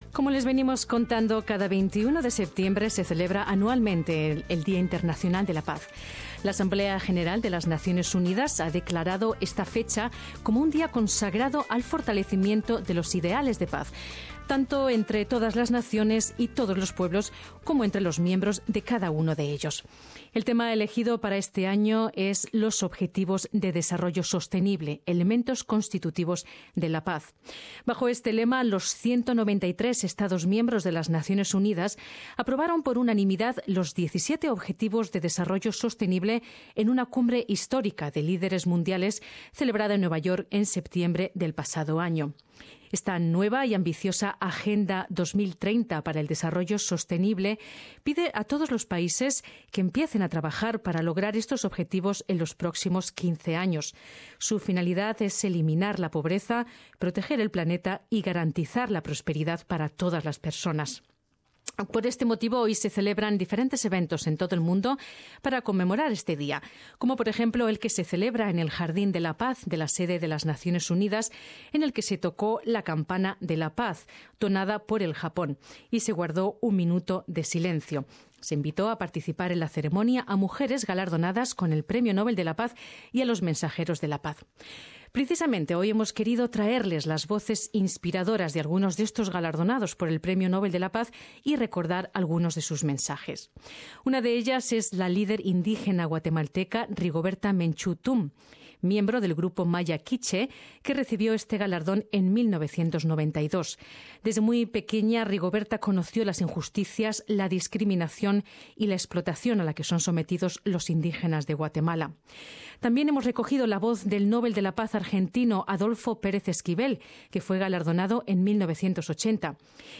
Escucha los mensajes inspiradores de los Premios Nobel de la Paz Rigoberta Menchú, Adolfo Pérez Esquivel y Malala Yousafzai